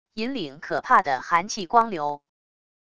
引领可怕的寒气光流wav音频